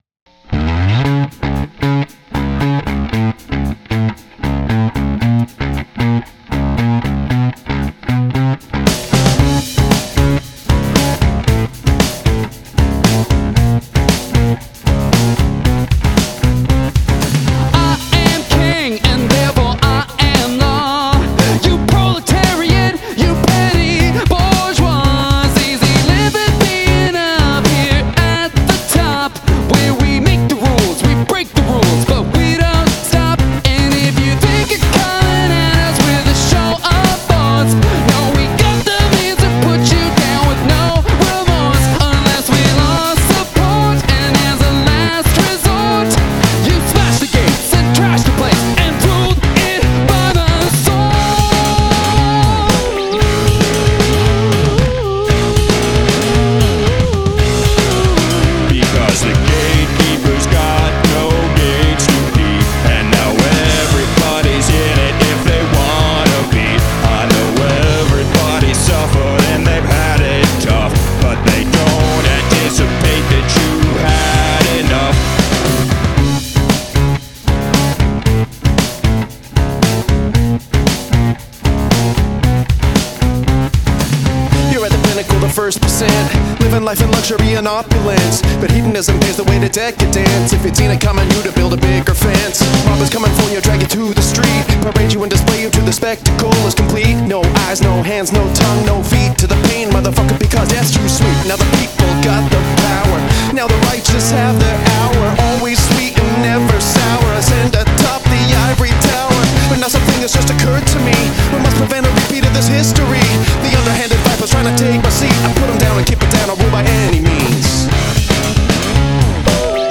I like how the track falls apart at the end.
Wow, that rocked.